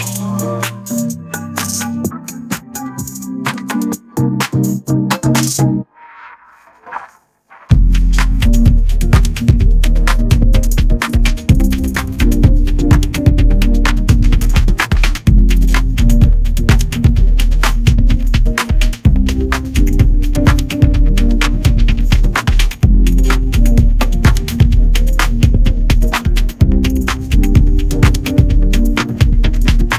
The output will be a generated audio file containing the music you requested, available in the specified audio format.